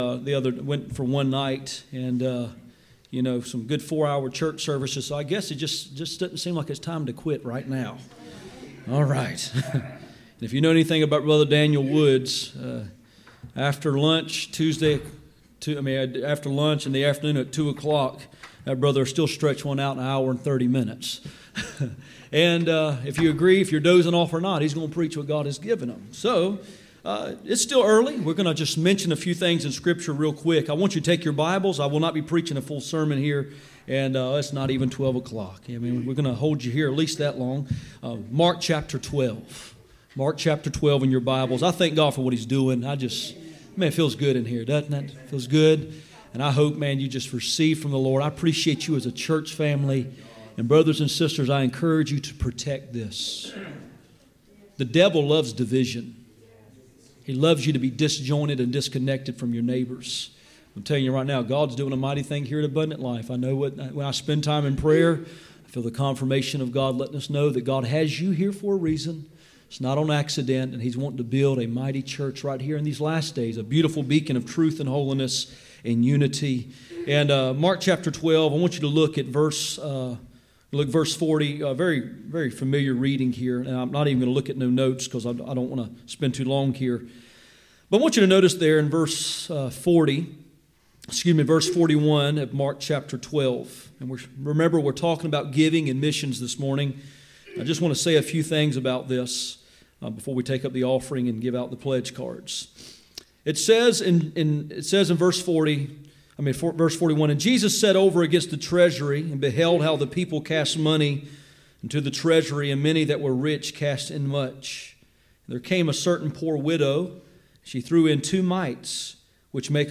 Passage: Mark 12:41-44 Service Type: Sunday Morning